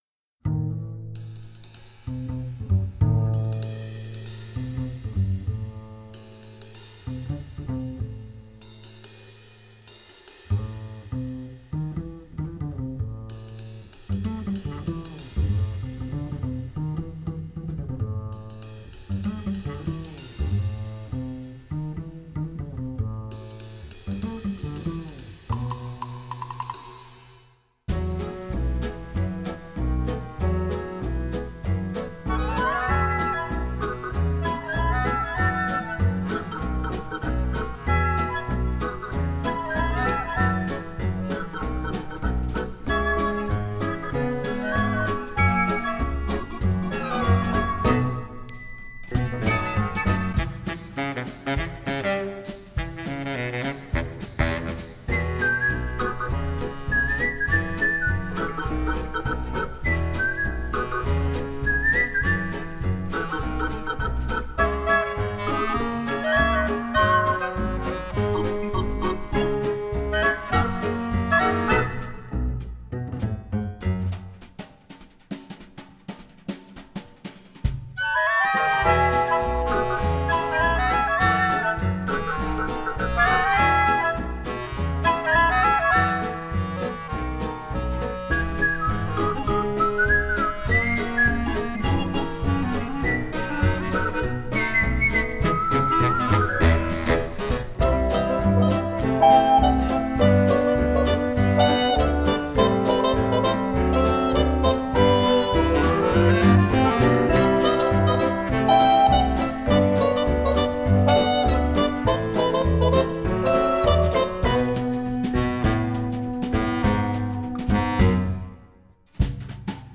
2009   Genre: Soundtrack   Artist